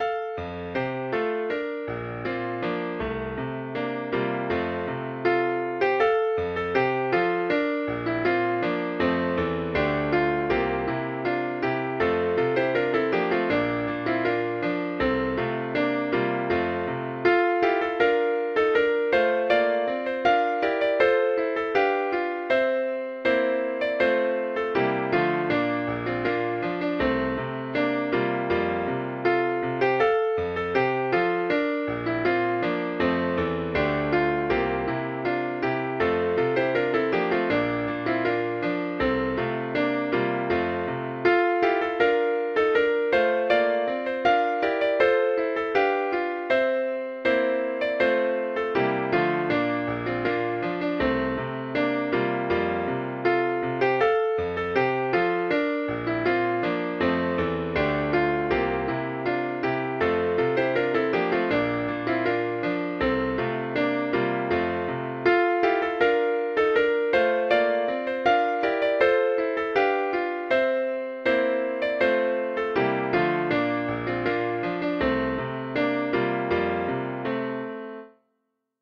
This is an old highland melody